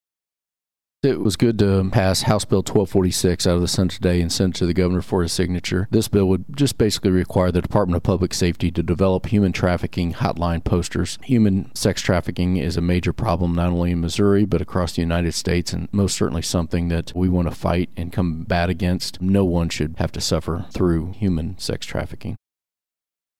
1. Senator Hoskins says House Bill 1246, which would require certain locations and businesses to post information regarding human trafficking, is on its way to the governor’s desk.